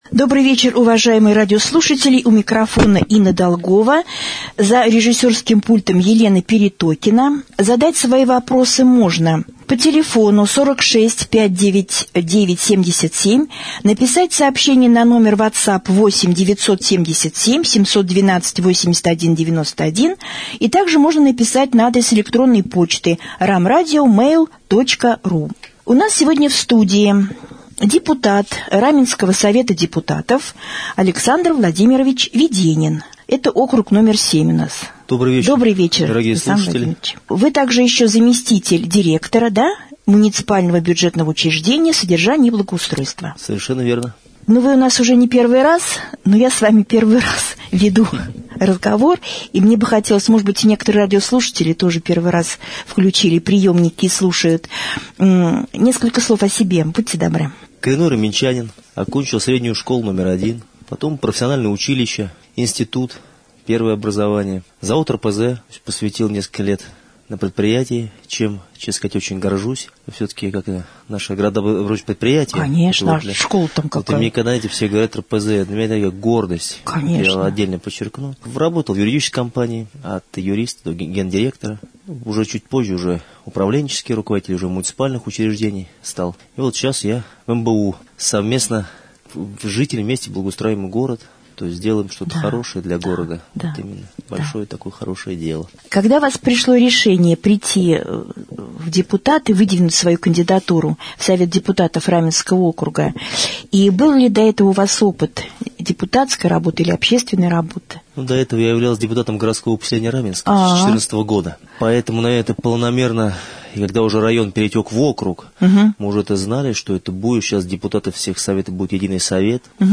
2.-prjamoj-jefir.mp3